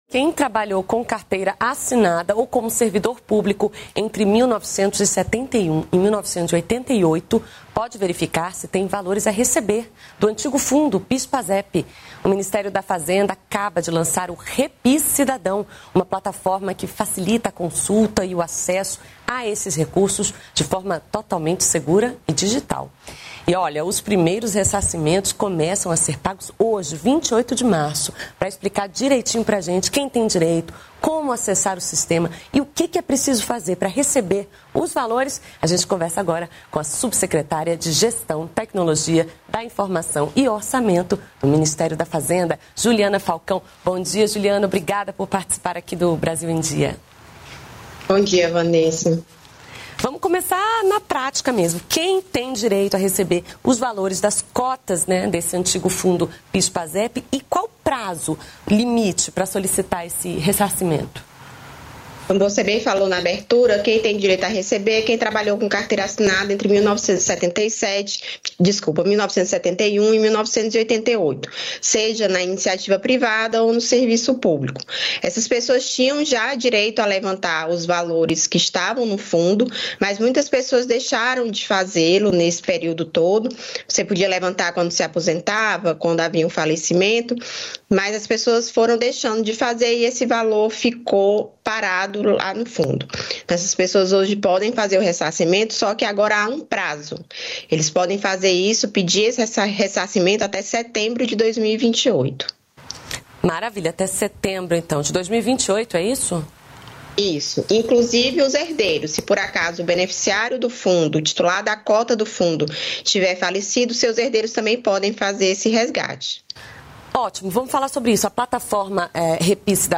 Brasil em Dia - Entrevista